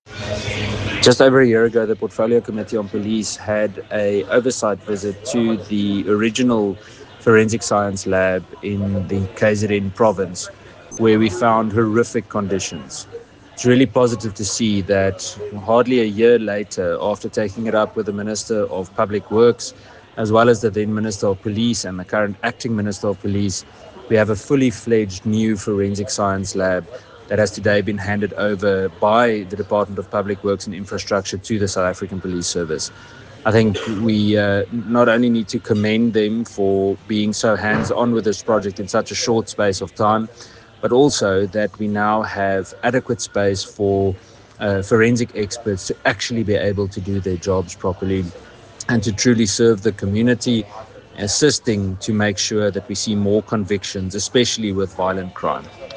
Soundbite by Ian Cameron MP
Cameron-17-April-Soundbite.ogg